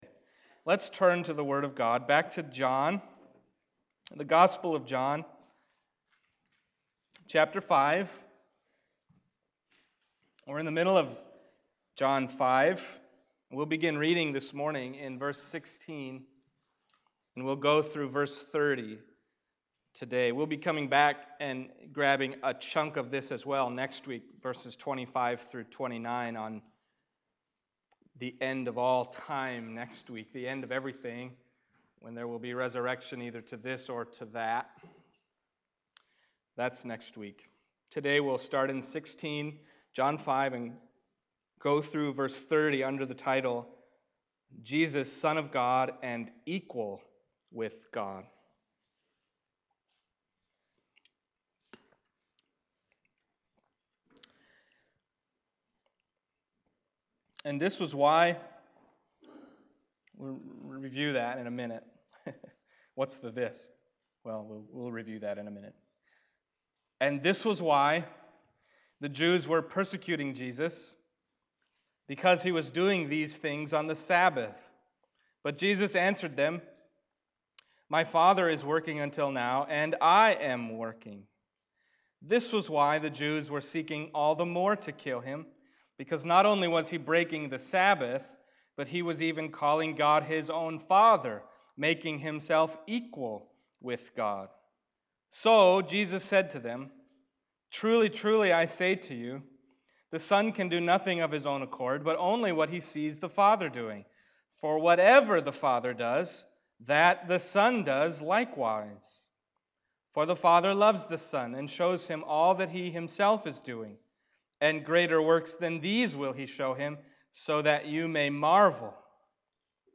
Passage: John 5:16-30 Service Type: Sunday Morning